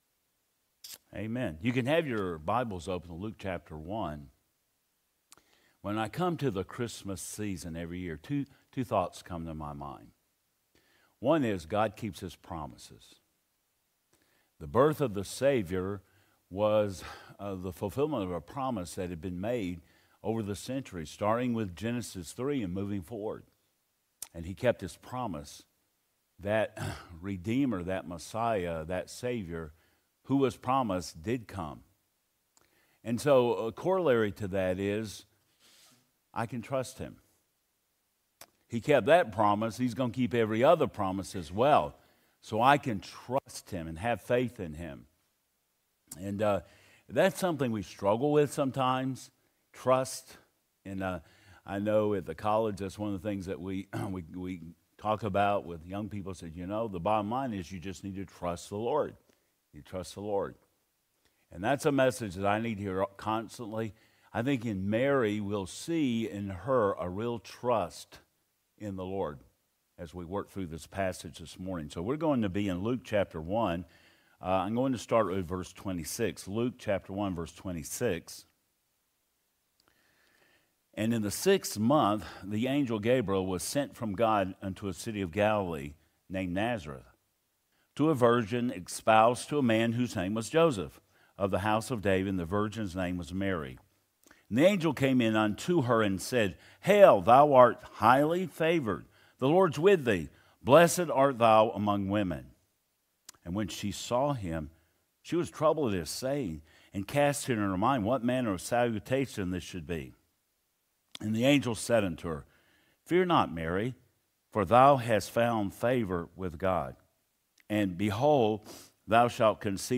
Passage: Luke 1:26 Service Type: Adult Sunday School Class « The Godhead What Makes a Gift So Great